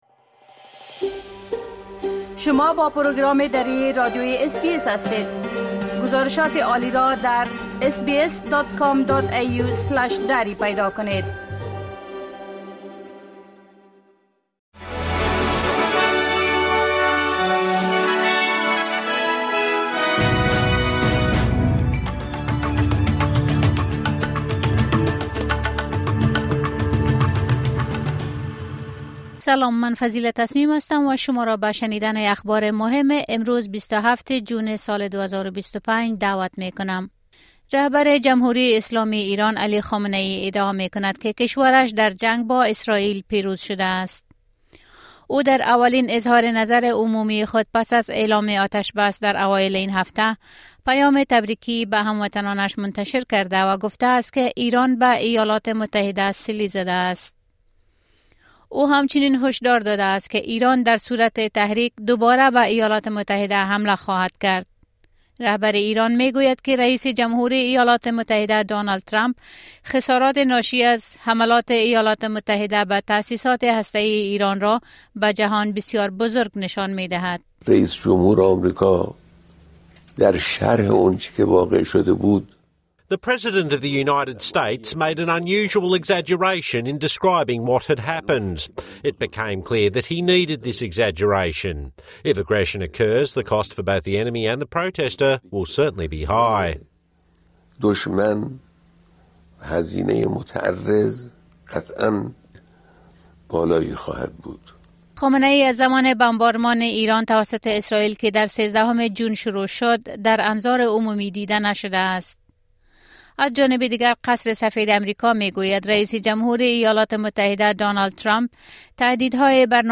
خلاصه مهمترين خبرهای روز از بخش درى راديوى اس‌بى‌اس | ۲۷ جون